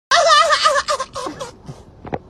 Memes
Zoeira Risada Do Rizzbot